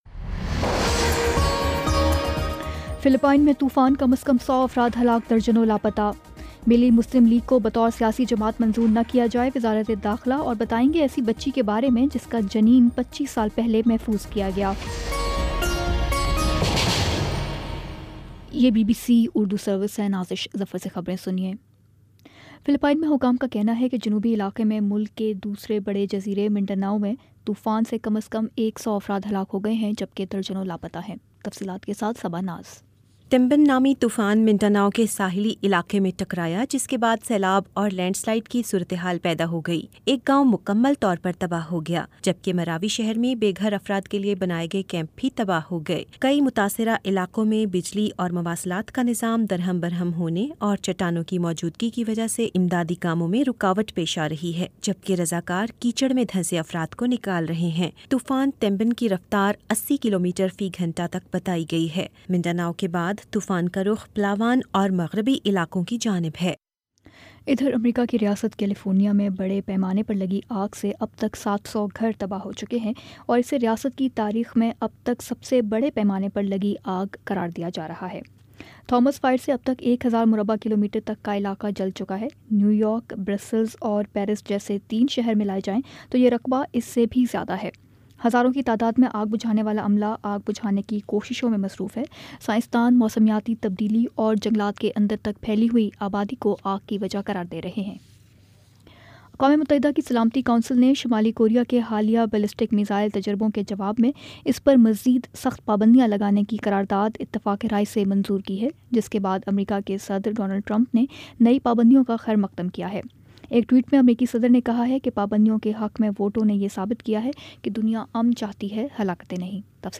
دسمبر 23 : شام چھ بجے کا نیوز بُلیٹن